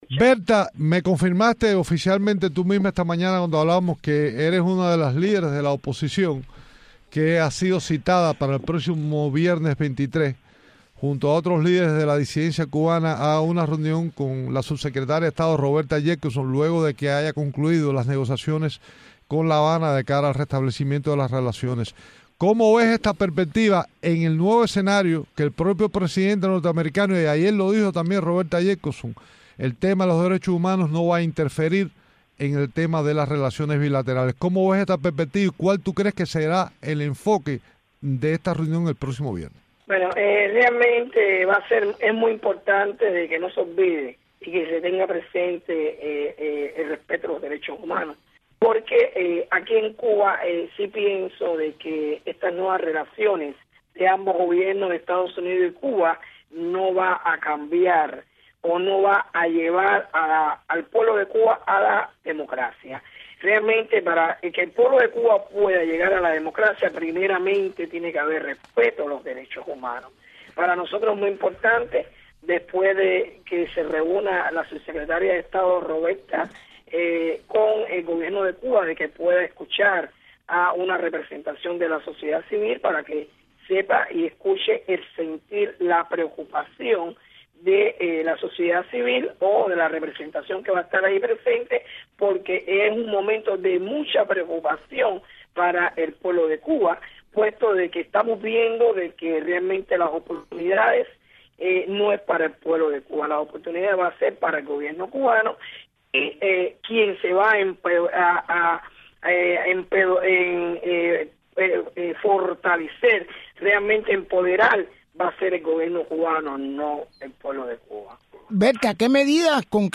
Declaraciones de Bertha Soler sobre reunión de opositores cubanos con Roberta Jacobson